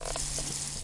卫生间 " 水
描述：感觉水从浴室的水龙头落下的声音。这个声音是在Tanger大楼的浴室使用Zoom H2 Handy Recorder录制的。